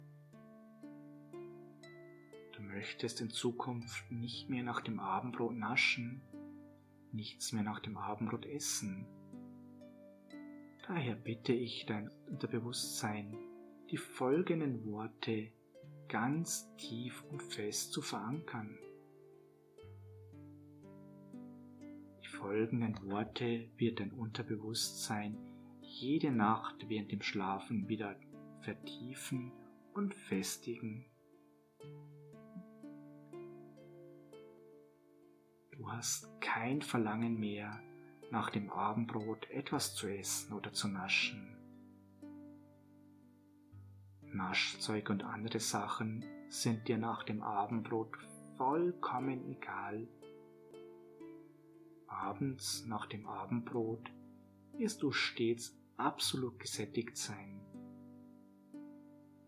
Mit Asmita Hypnose haben Sie hierfür die ideale Lösung – geführte Hypnose zur Gewichtsabnahme und Gesundheitsförderung. Beginnen Sie Ihre Reise zu Ihrem Wunschgewicht und erleben Sie dabei eine herrliche Einleitung, welche Sie in eine angenehme Entspannung führt.